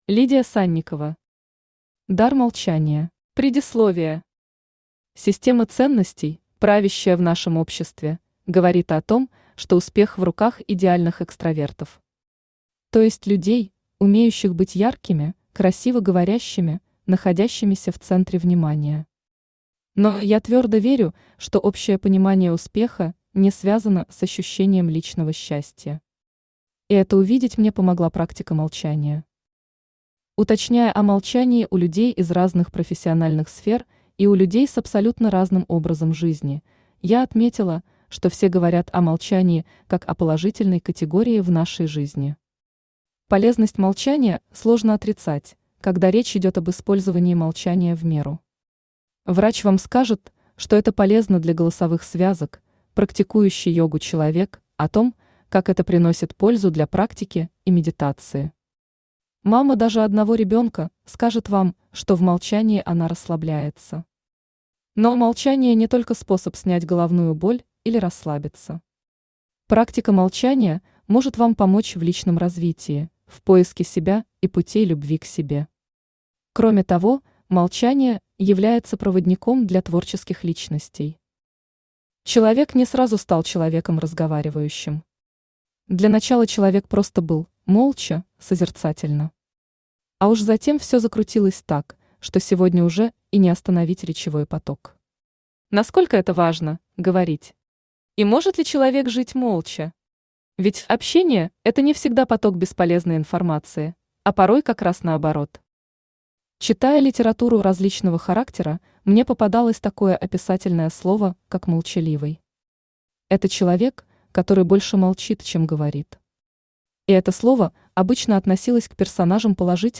Аудиокнига Дар молчания | Библиотека аудиокниг
Aудиокнига Дар молчания Автор Лидия Санникова Читает аудиокнигу Авточтец ЛитРес.